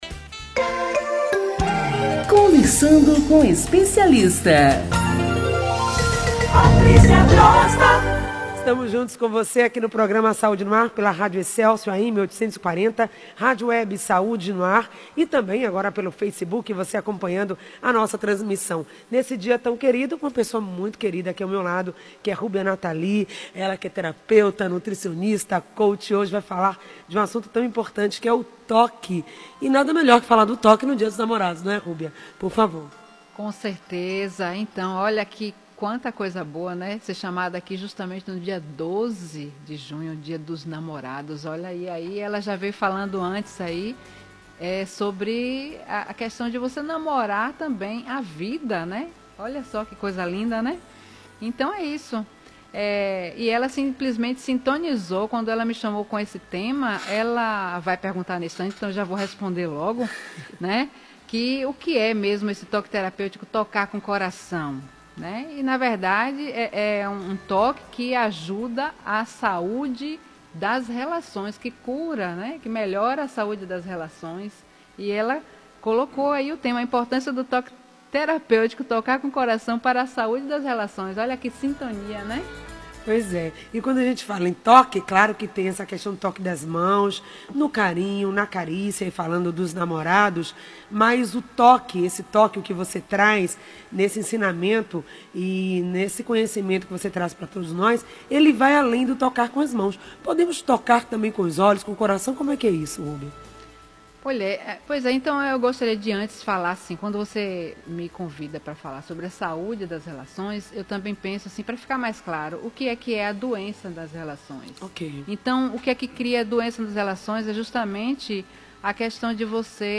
Ouça a entrevista na íntegra ou assista ao vídeo no Facebook